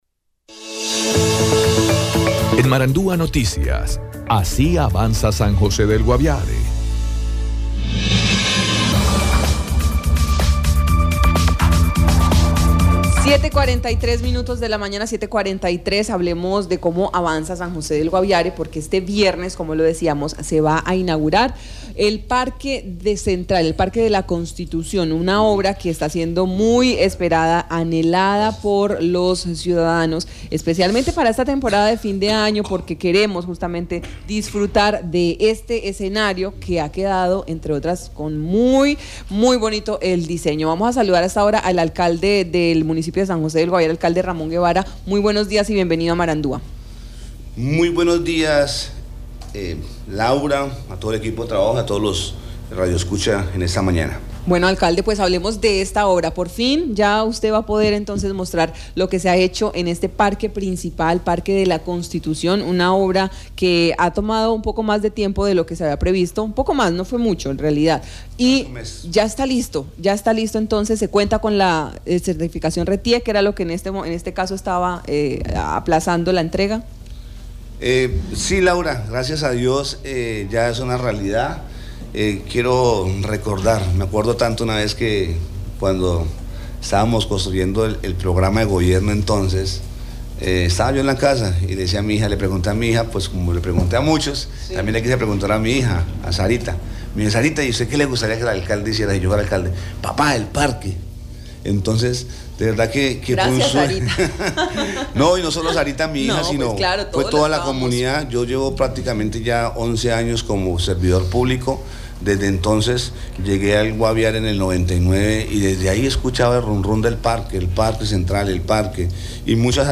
Ramón Guevara, alcalde de San José del San José del Guaviare se refirió inicialmente a la obra del Parque de la Constitución lugar emblemático para los habitantes de la capital del Guaviare.